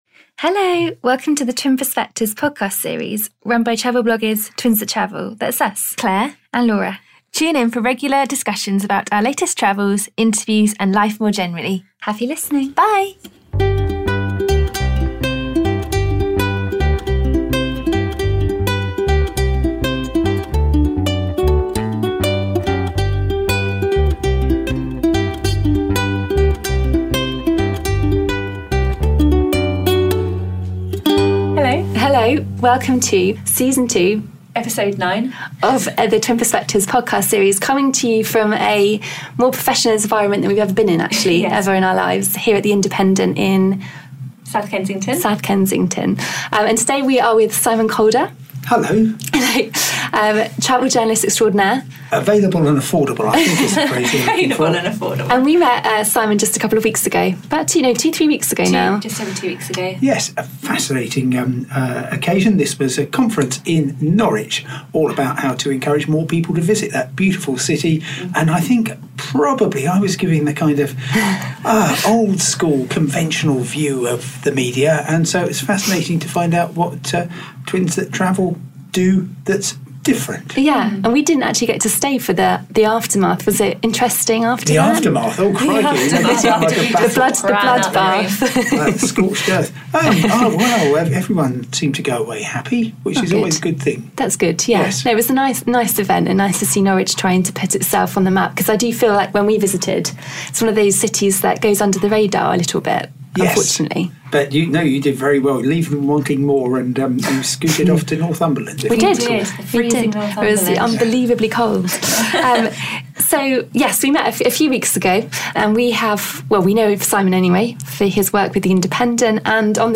An Interview with Travel Writer Simon Calder
In this week's episode of the Twin Perspective podcast series, we leave the confines of our desk and head to the slightly intimidating offices of The Independent, to meet travel writer and presenter, Simon Calder.